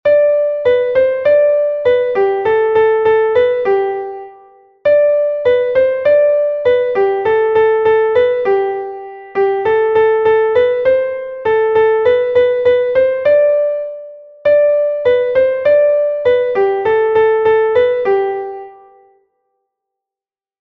Meldie: Volksweise